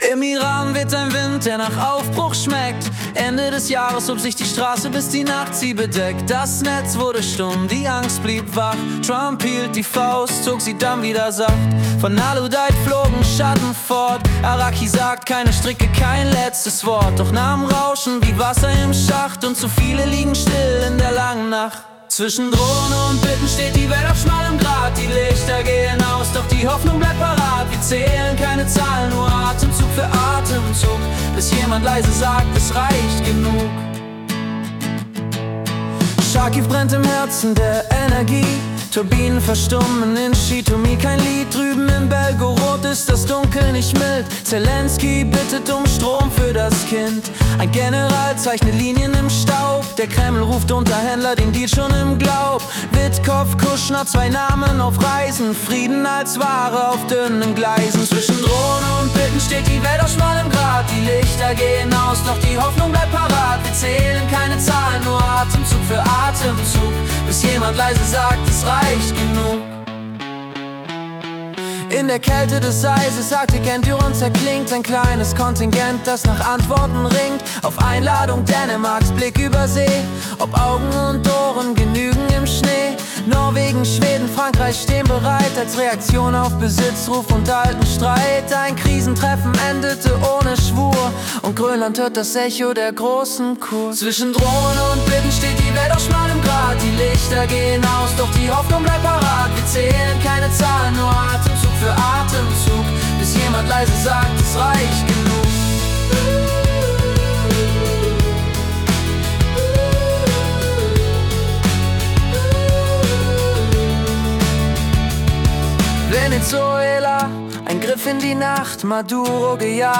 Januar 2026 als Singer-Songwriter-Song interpretiert.